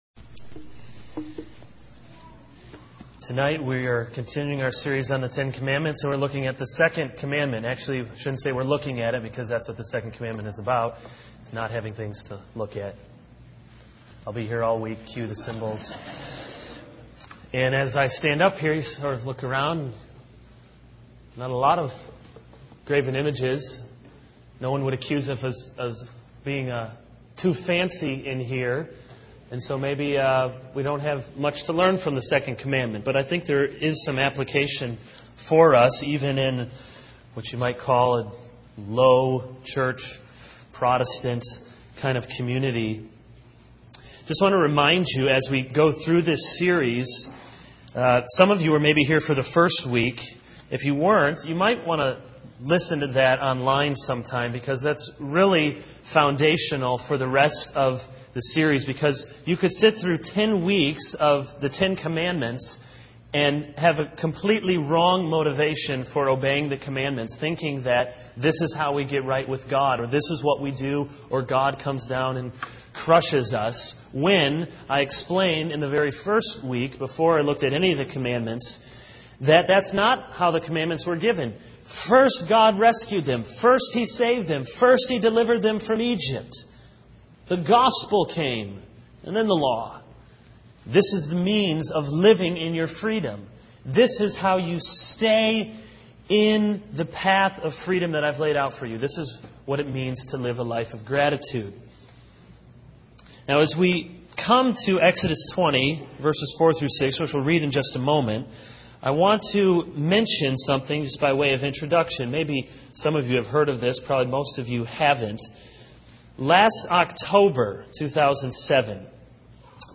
This is a sermon on Exodus 20:1-17 - No graven images.